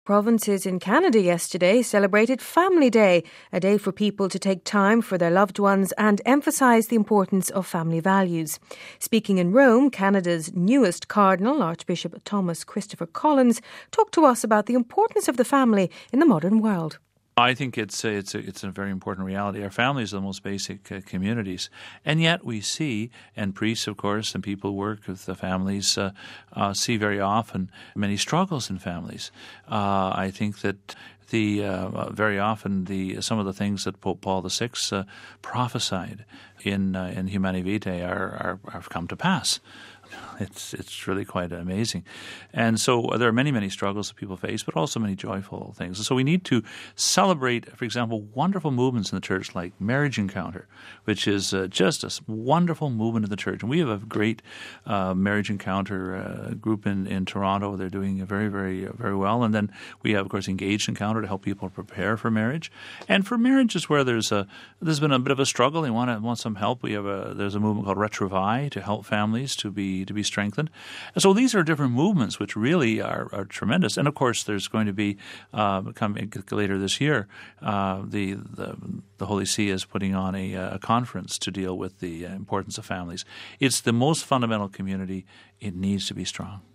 Speaking in Rome, Canada’s newest Cardinal, Archbishop Thomas Christopher Collins talked to us about the importance of the family in the modern world.